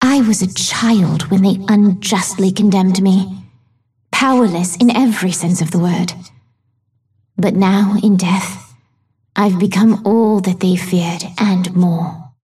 Vindicta voice line - I was a child when they unjustly condemned me.